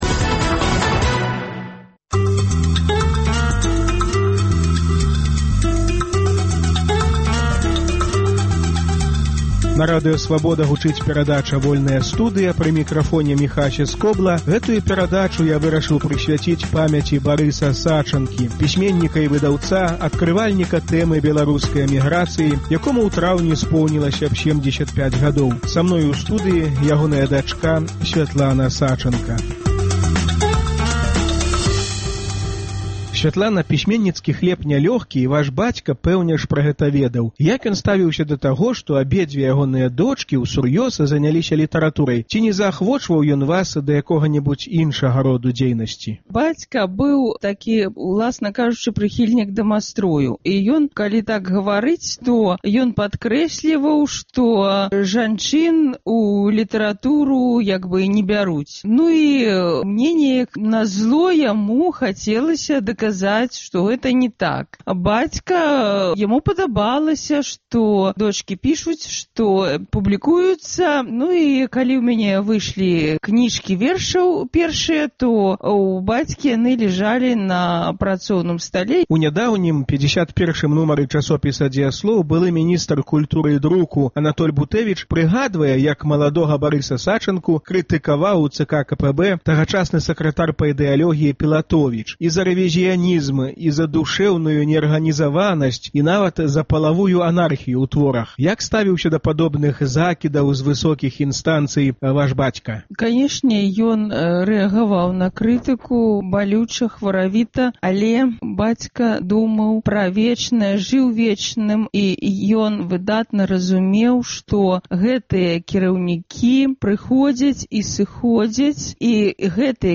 Энцыкляпэдыст, які не паддаўся: да 75-х угодкаў Барыса Сачанкі. Гутарка